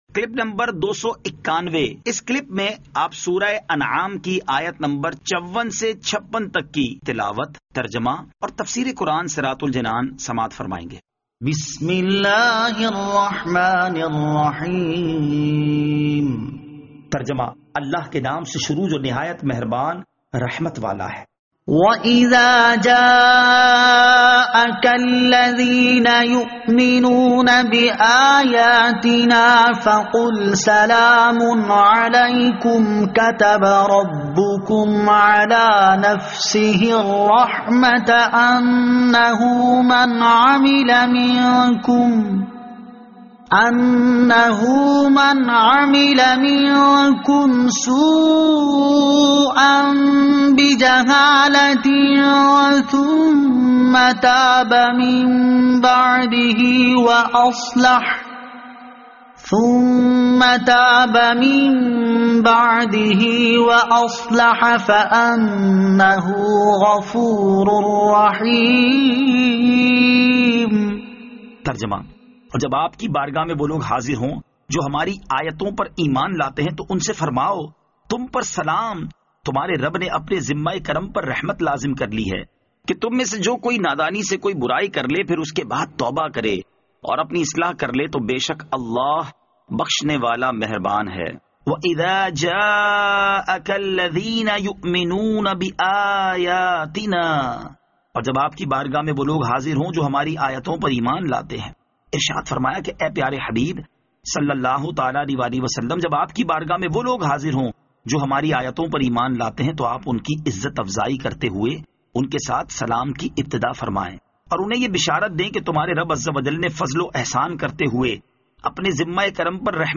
Surah Al-Anaam Ayat 54 To 56 Tilawat , Tarjama , Tafseer
2021 MP3 MP4 MP4 Share سُوَّرۃُ الأنعام آیت 54 تا 56 تلاوت ، ترجمہ ، تفسیر ۔